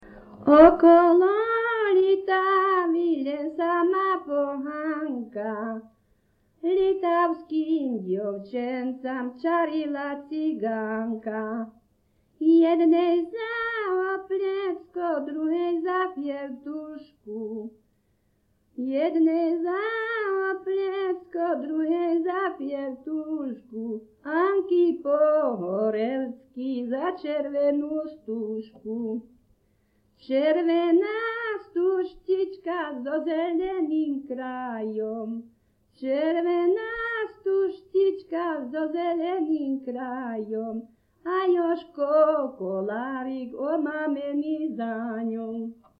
Popis sólo ženský spev bez hudobného sprievodu
Miesto záznamu Litava
Kľúčové slová ľudová pieseň